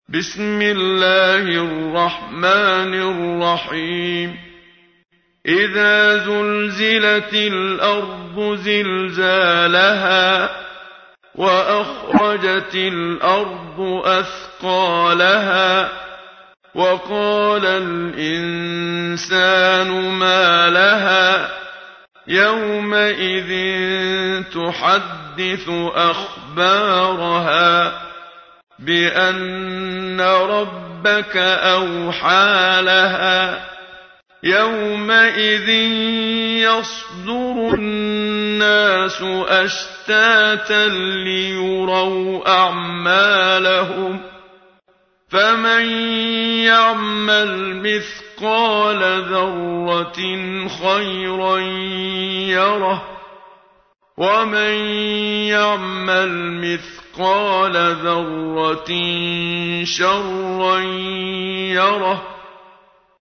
تحميل : 99. سورة الزلزلة / القارئ محمد صديق المنشاوي / القرآن الكريم / موقع يا حسين